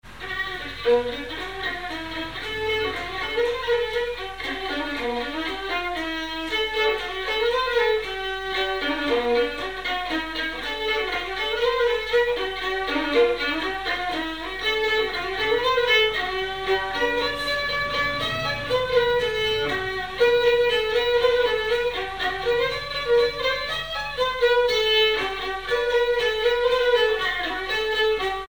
Marche
danse : marche
circonstance : conscription
Pièce musicale inédite